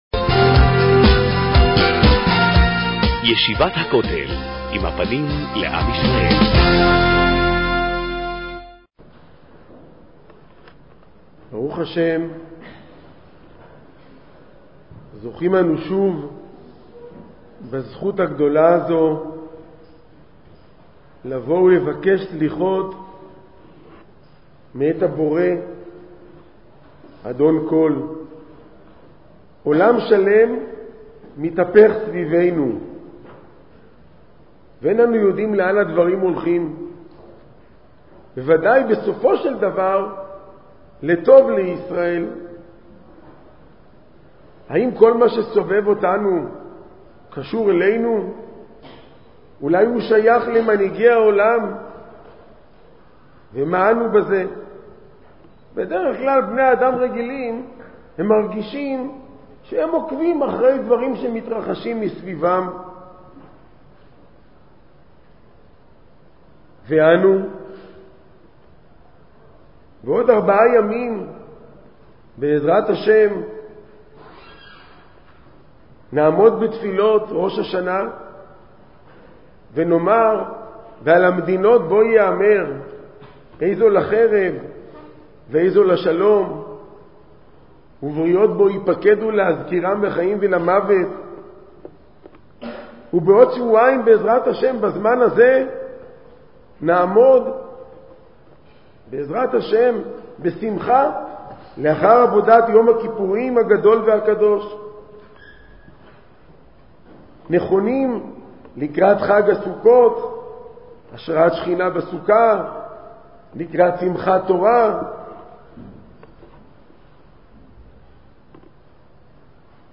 שיחה לפני סליחות